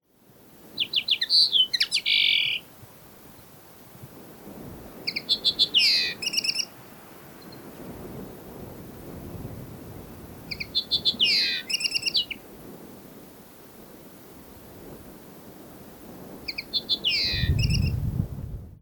Sang